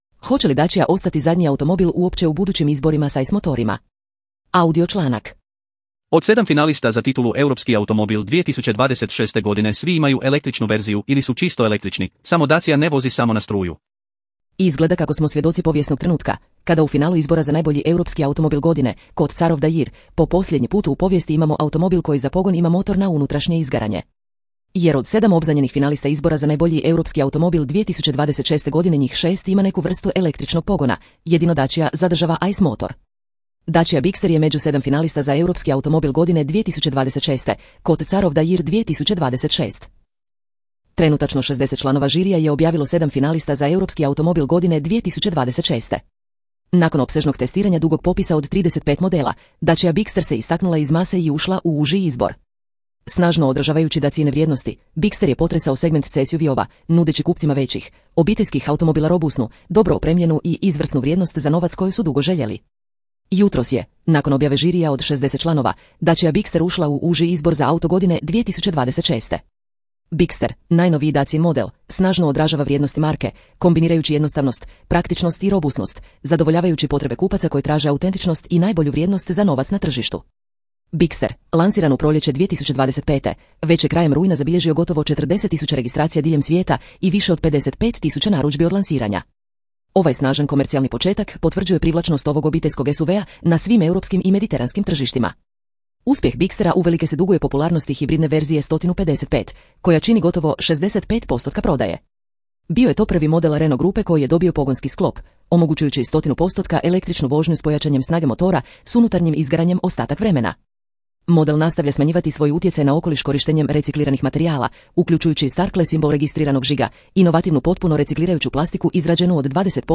Hoće li Dacia ostati zadnji automobil uopće u budućim izborima s ICE motorima? (audio članak)